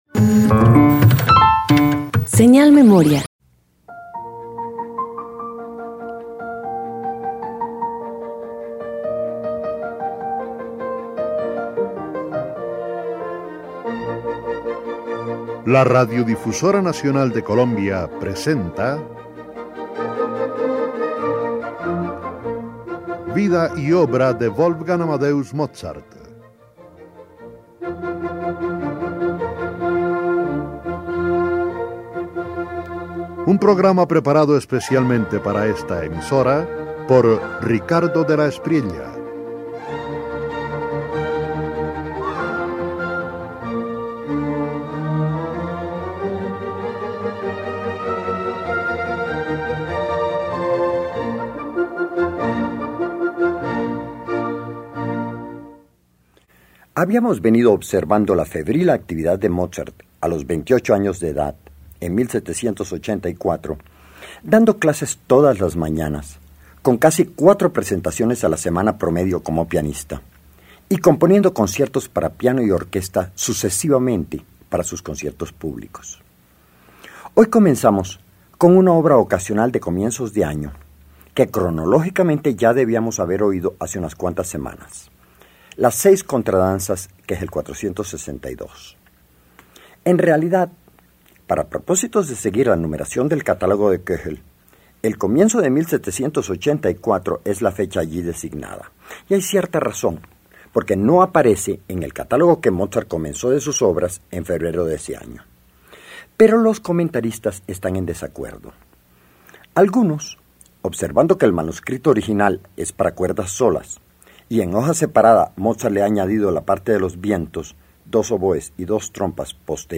Radio colombiana
Concierto para piano y orquesta